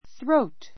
throat θróut す ロ ウ ト 名詞 喉 のど ⦣ 首 （neck） の前面または内部をいう.